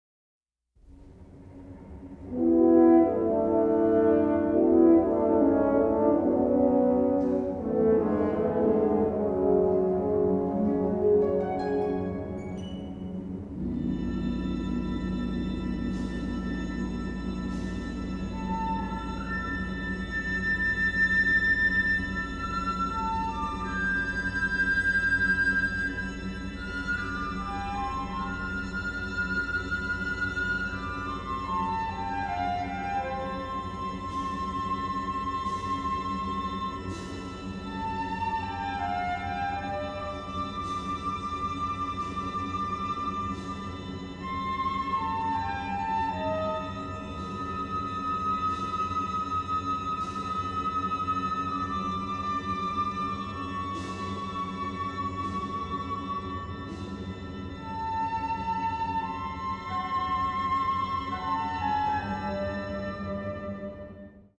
recorded at CTS Studios in Wembley, England